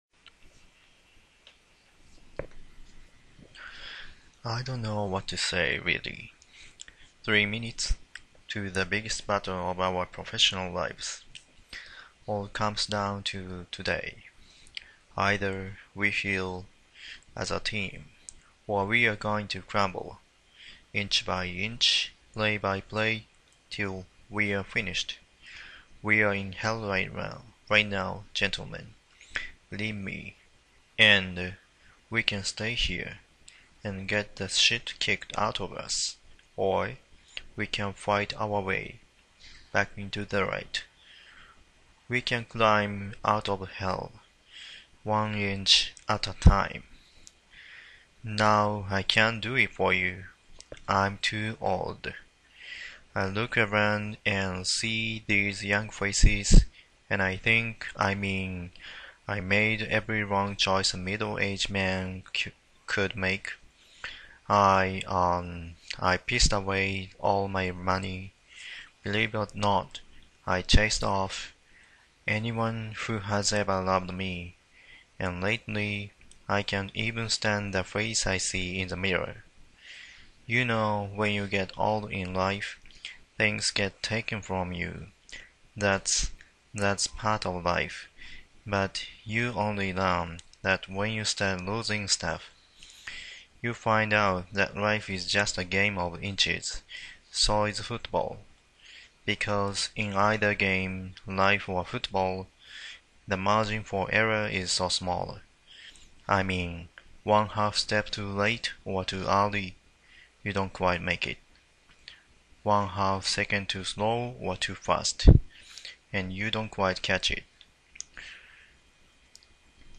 Inch by inch speech